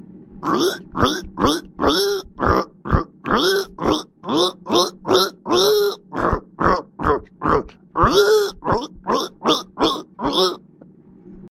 دانلود صدای حیوانات 51 از ساعد نیوز با لینک مستقیم و کیفیت بالا
جلوه های صوتی